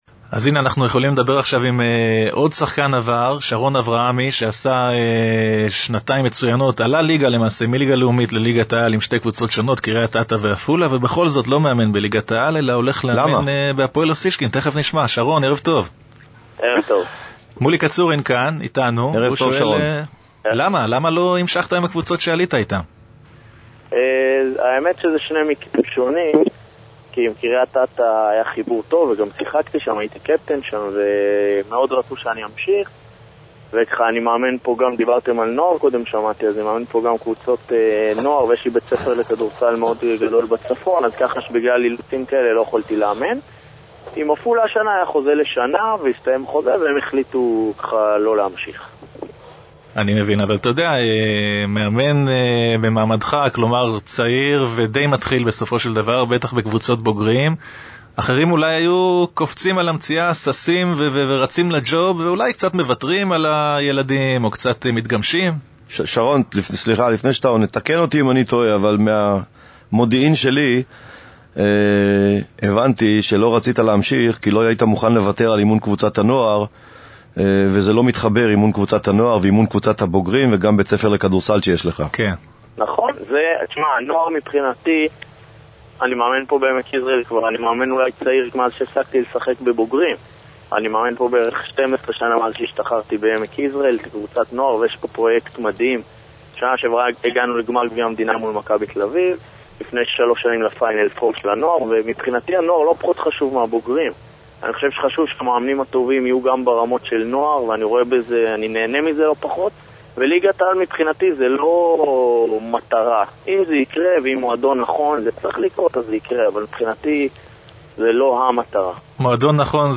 שמונה ראיונות מוקלטים באייטמון כדורסלע אחד, מתוך התוכנית 'רק ספורט' ב-99FM.
את שאלות המאזינים וקטעי הקישור שבין ראיון לראיון באולפן הורדתי, יען כי רבים הם עד מאוד ועד בלי די ועד אין קץ.